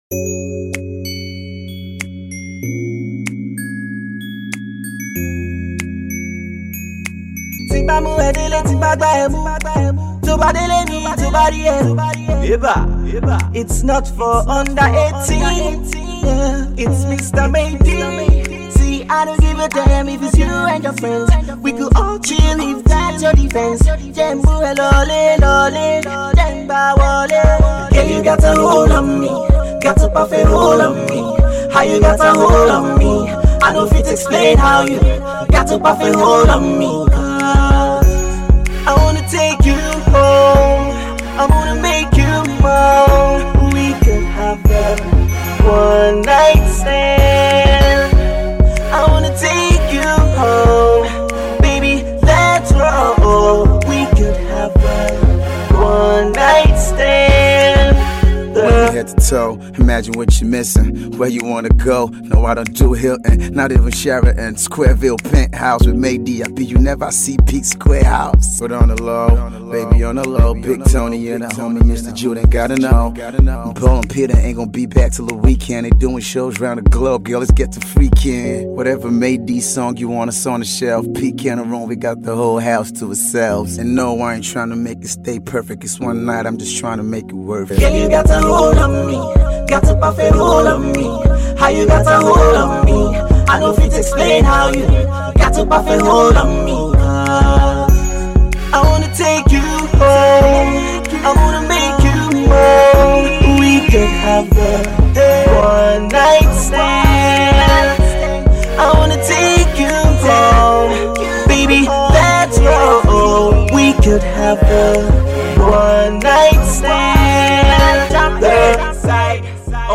chilled out tune